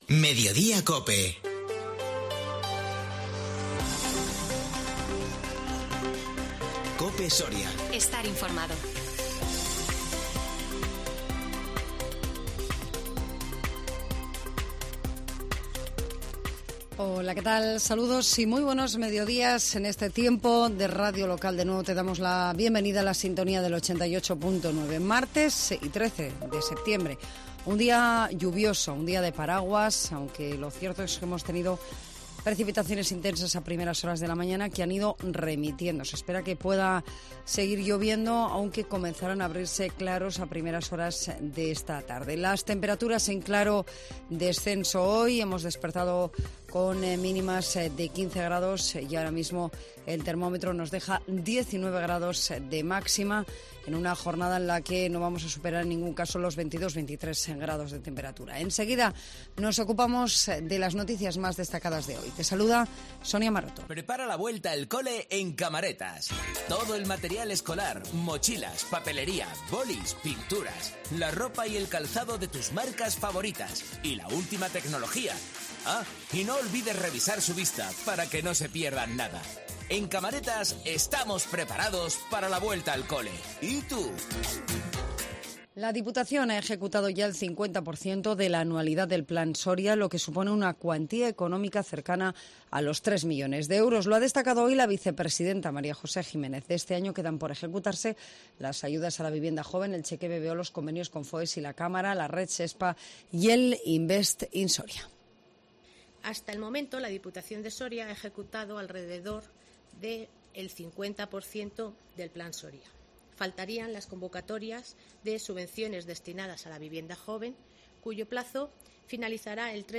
INFORMATIVO MEDIODÍA COPE SORIA 13 SEPTIEMBRE 2022